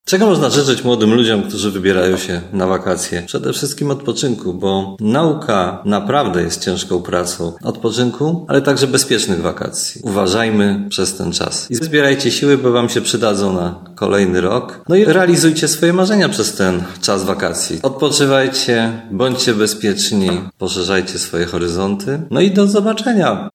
Mówił prezydent Tarnobrzega Dariusz Bożek.